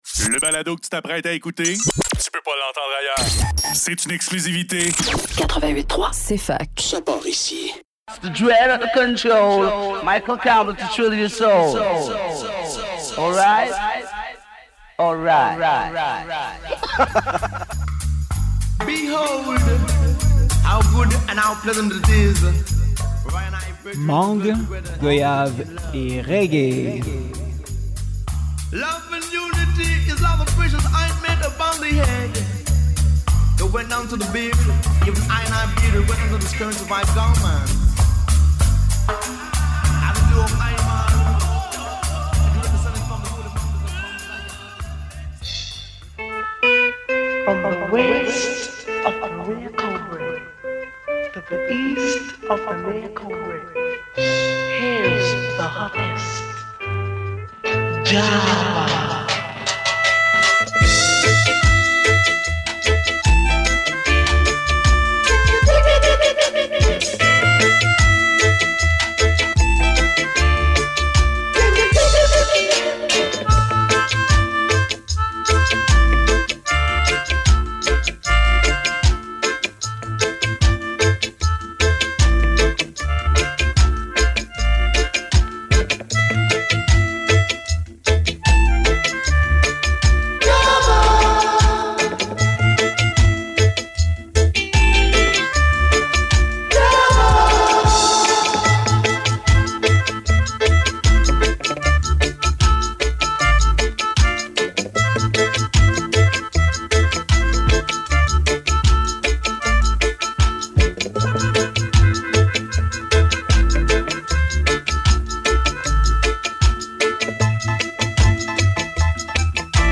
Roots Reggae